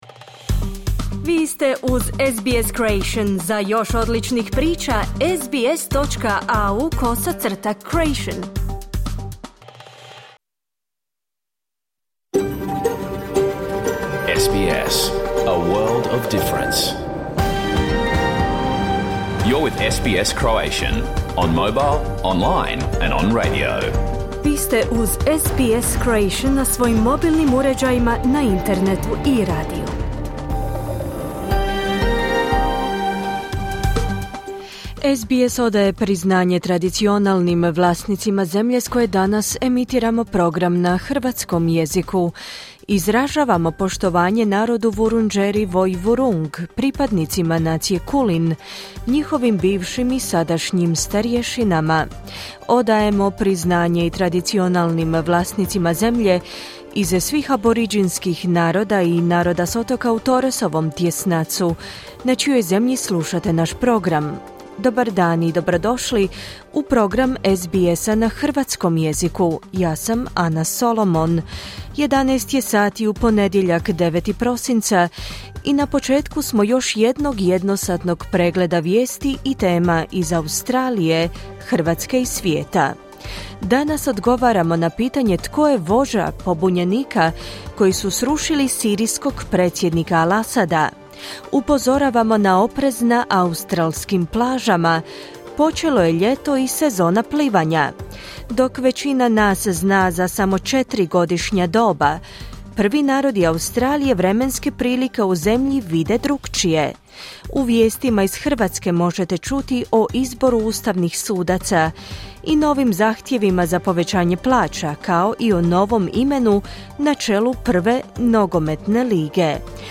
Vijesti i aktualnosti iz Australije, Hrvatske i svijeta. Emitirano na radiju SBS1 u 11 sati, po istočnoaustralskom vremenu.